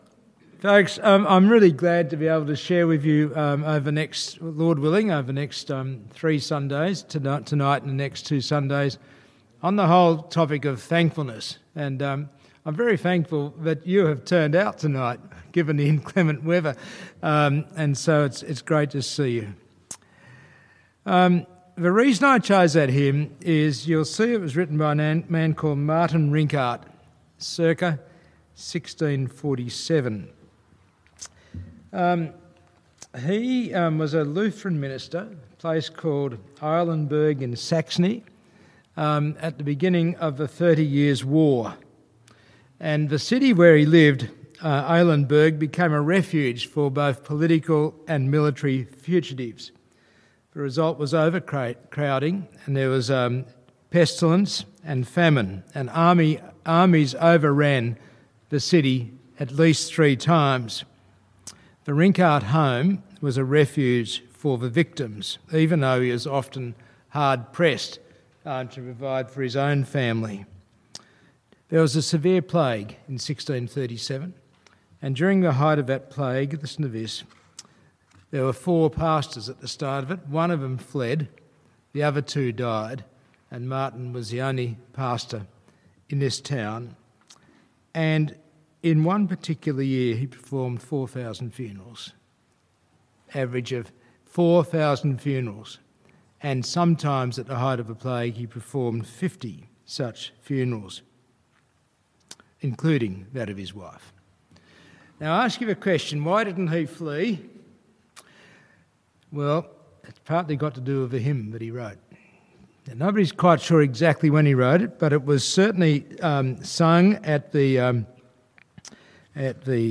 Passage: Psalm 100 Service Type: Sunday evening service